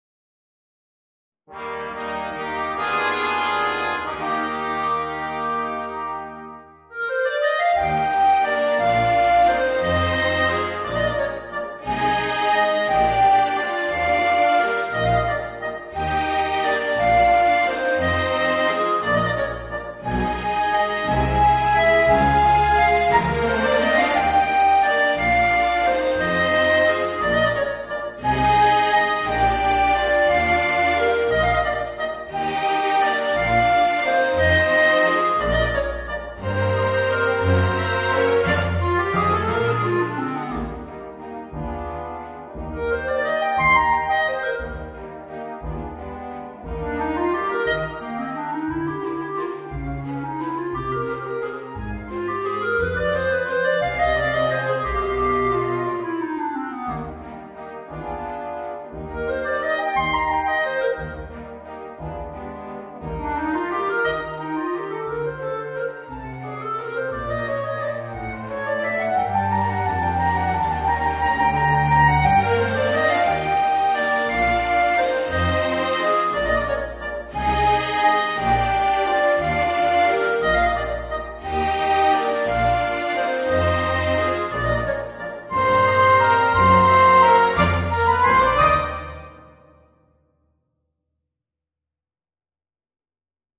Voicing: Horn and Orchestra